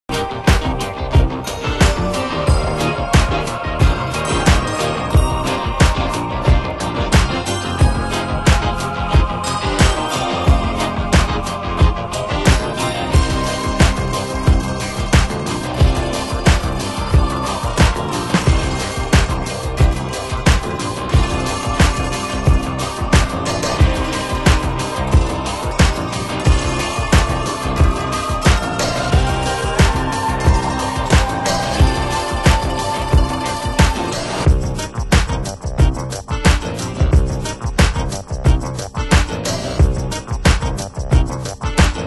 ゆっくりと展開する前半部分、中盤からコーラス＆シンセも加わりスケール・アップするコズミックDISCO！